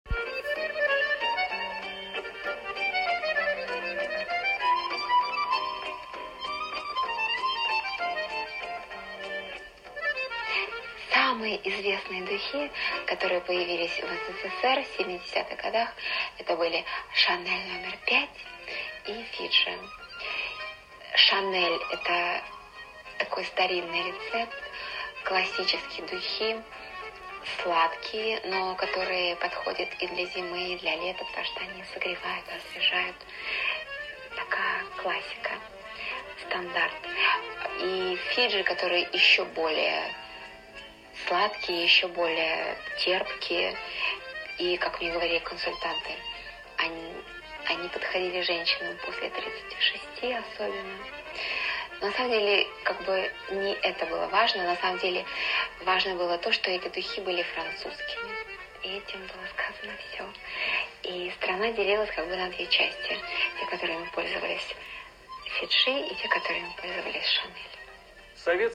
Здравствуйте!  Помогите, пожалуйста, опознать французский шансон